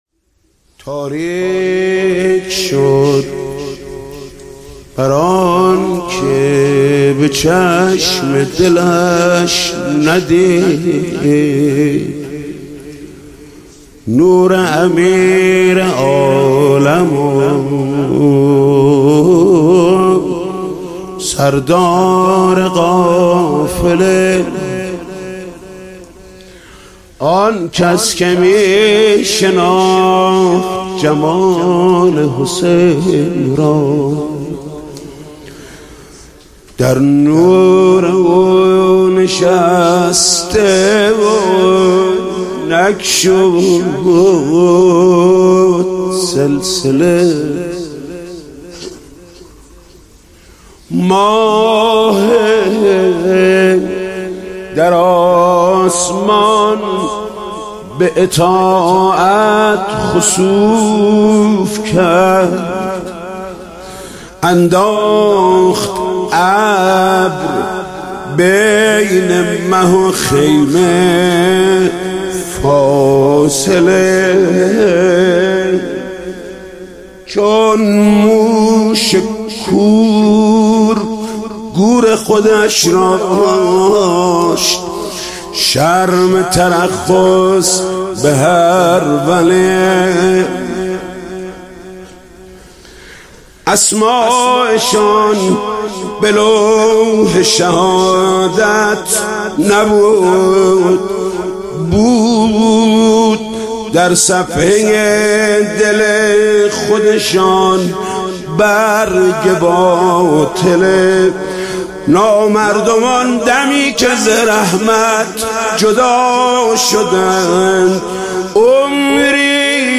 مداحی جدید حاج محمود کريمی شب پنجم محرم97 هيأت راية العباس